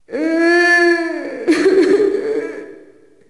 Index of /Downloadserver/sound/zp/zombie/female/